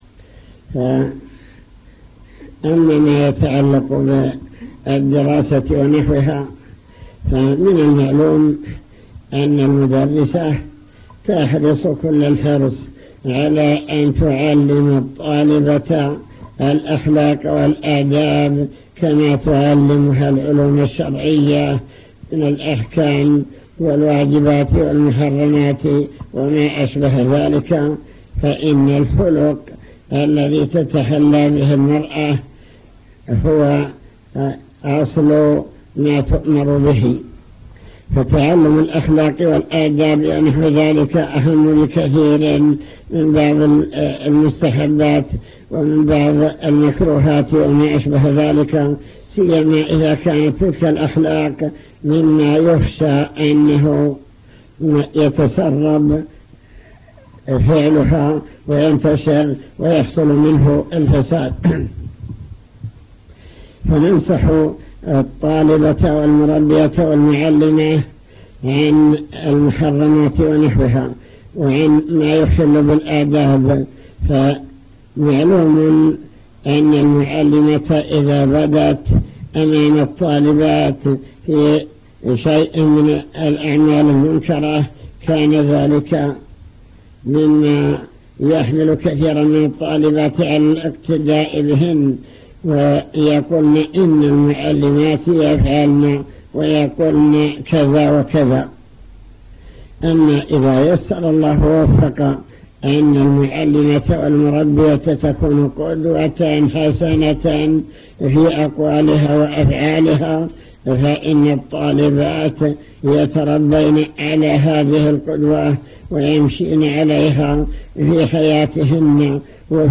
المكتبة الصوتية  تسجيلات - محاضرات ودروس  محاضرة بكلية البنات